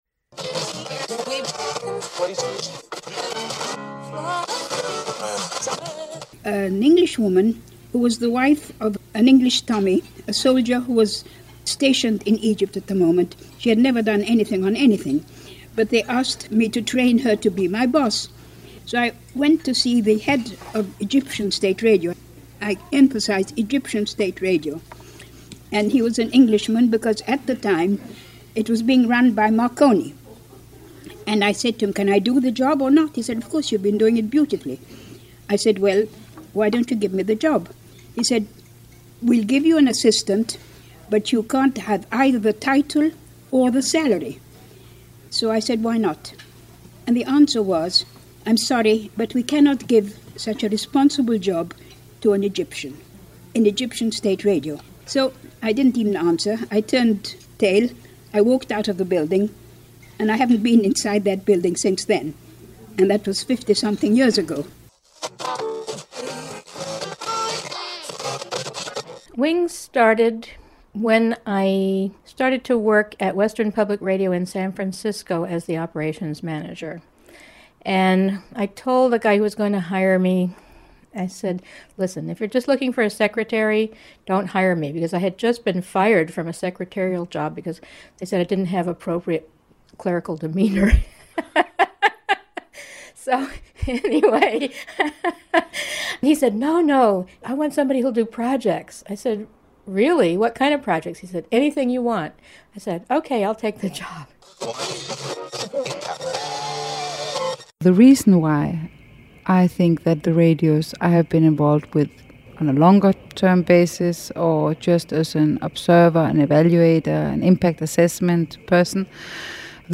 Roundtable discussion and more feat Community Voices